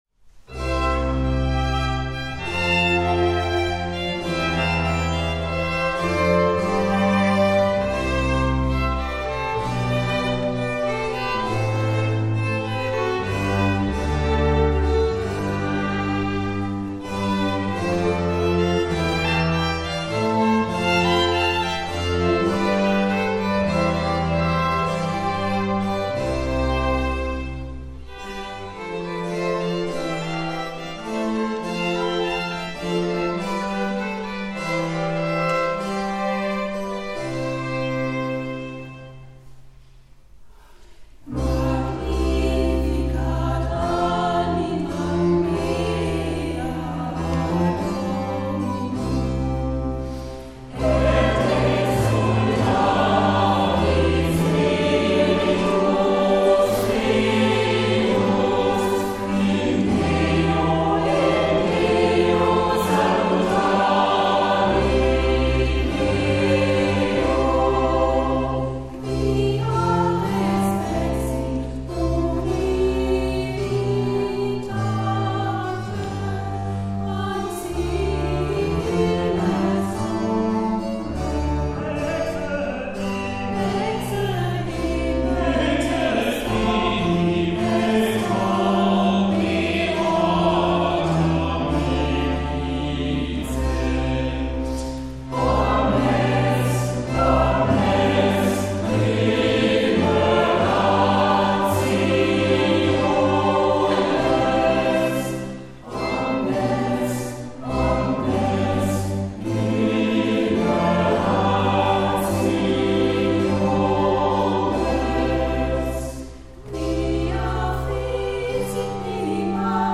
Der Chor von St. Josef singt eine lateinische Magnificatvertonung eines unbekannten Komponisten des 17. Jahrhunderts. Die Aufnahme stammt aus dem Konzertmitschnitt des Adventskonzertes vom 15.12.2019.
Chor von St. Josef
Solostimmen
Violinen
Viola
Violoncello
Kontrabass
Cembalo